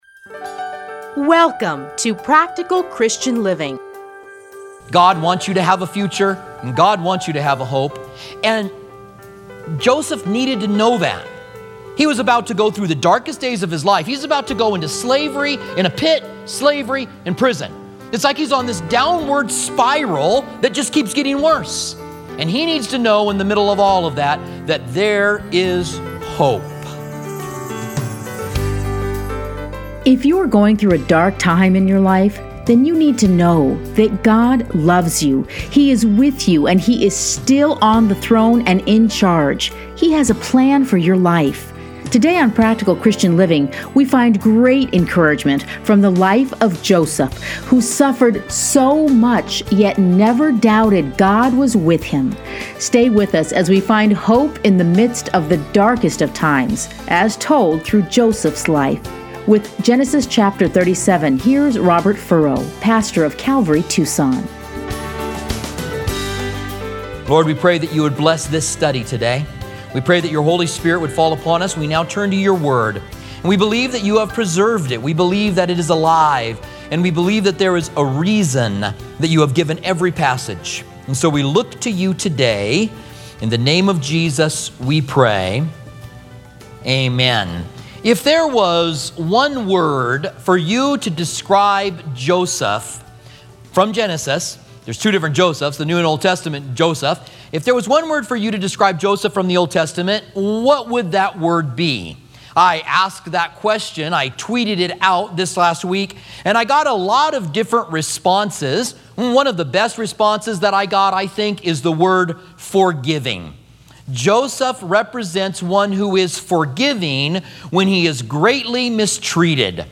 Listen here to a teaching from Genesis.